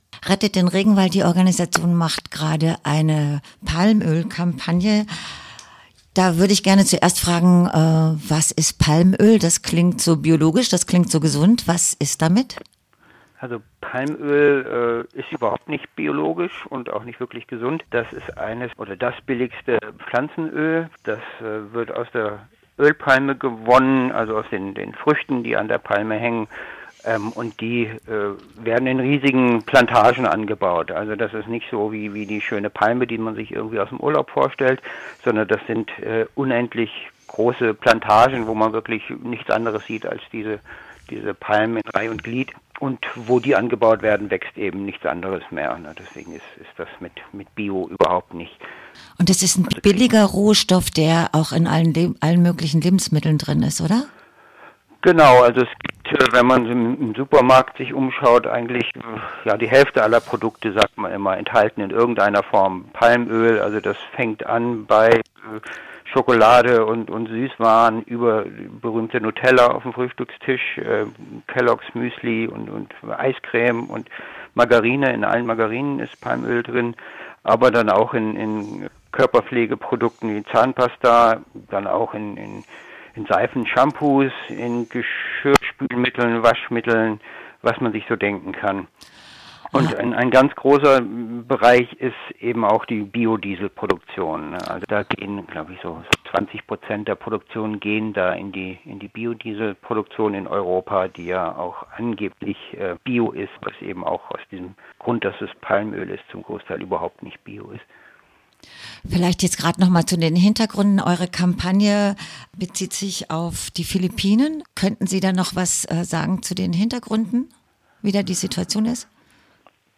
ein Interview mit einem Mitarbeiter von "Rettet den Regenwald" Hamburg über den Massenanbau von Palmöl in Palawan (Phillippinen), den Landraub und den Widerstand dagegen.